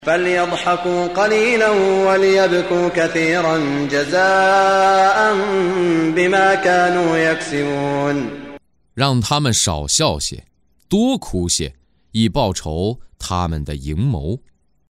中文语音诵读的《古兰经》第（讨拜）章经文译解（按节分段），并附有诵经家沙特·舒拉伊姆的朗诵